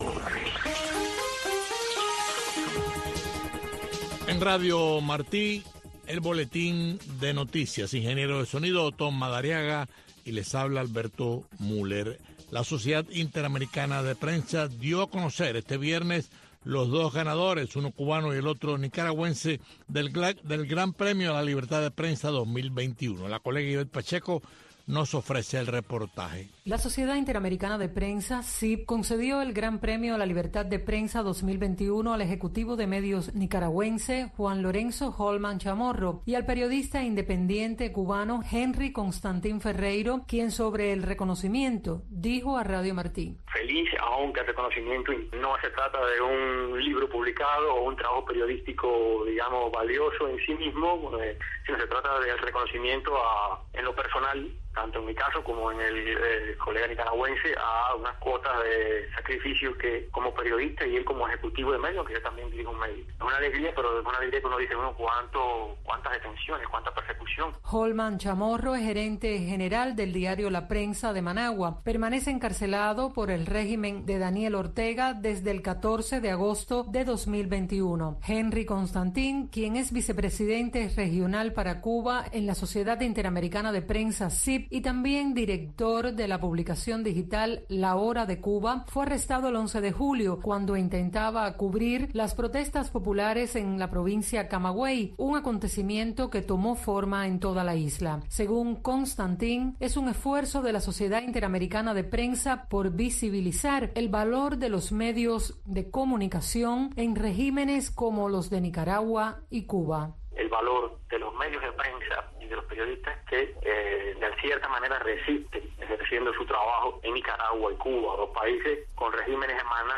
Entrevistas e informaciones con las voces de los protagonistas desde Cuba. Servirá de enlace para el cubano conozca lo que sucede en el país sin censura. La Voz de la oposición